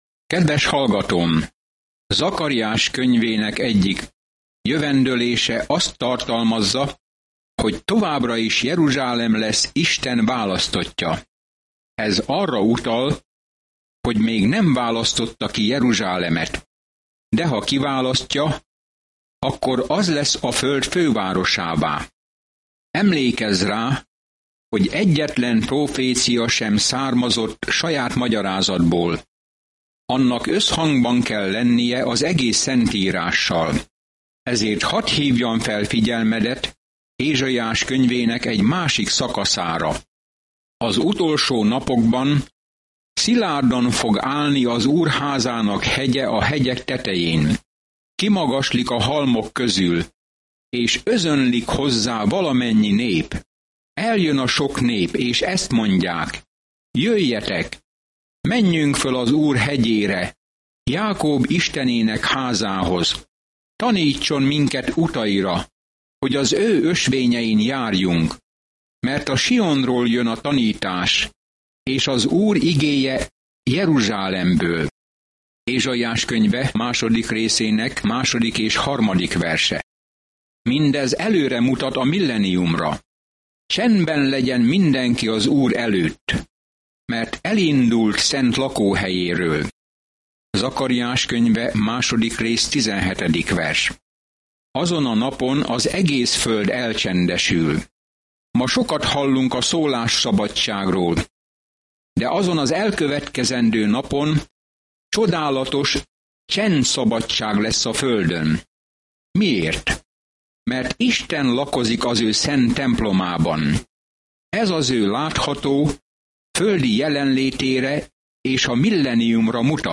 Szentírás Zakariás 3:1-3 Nap 4 Olvasóterv elkezdése Nap 6 A tervről Zakariás próféta látomásokat oszt meg Isten ígéreteiről, hogy reményt adjon az embereknek a jövőre nézve, és arra buzdítja őket, hogy térjenek vissza Istenhez. Napi utazás Zakariáson keresztül, miközben hallgatod a hangos tanulmányt, és olvasol válogatott verseket Isten szavából.